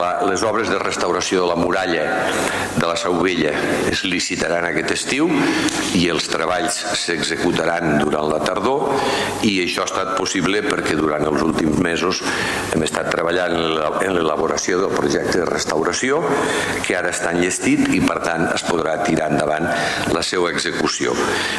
tall-de-veu-de-lalcalde-miquel-pueyo-sobre-les-obres-de-les-muralles